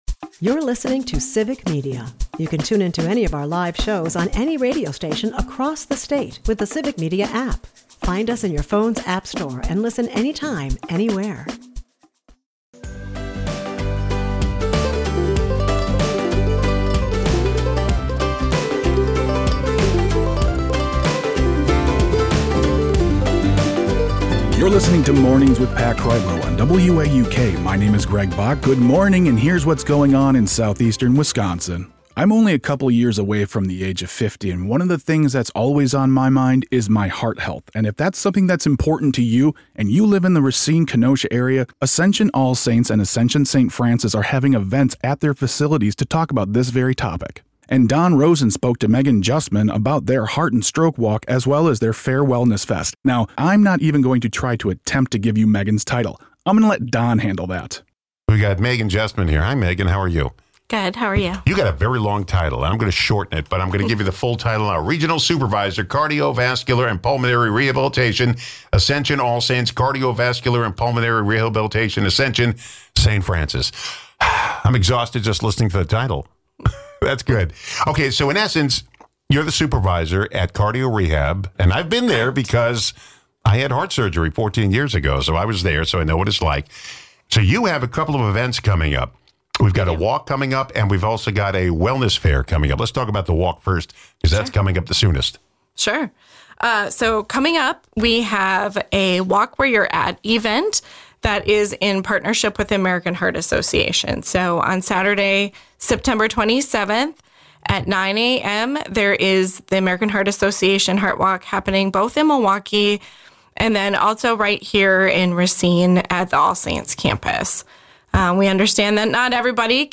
WAUK Morning Report is a part of the Civic Media radio network and air four times a morning.